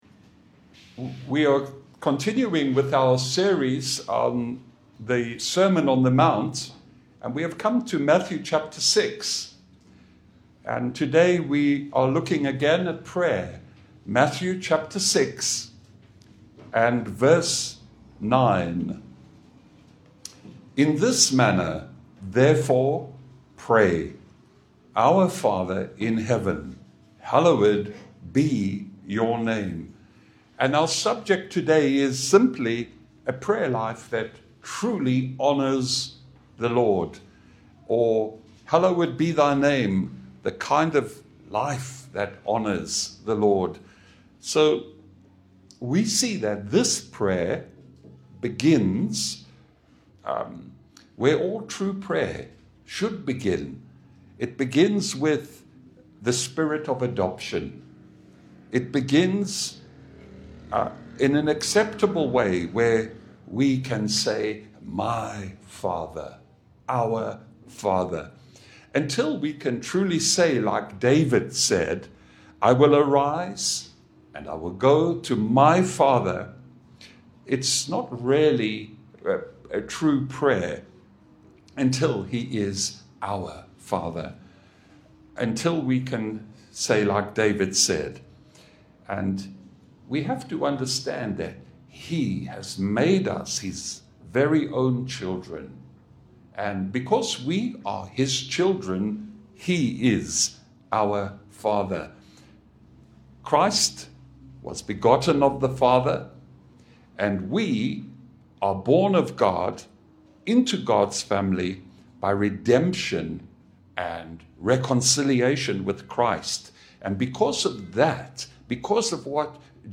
Matthew 6:9 Service Type: Sunday Bible fellowship « Where do wars and fights come from?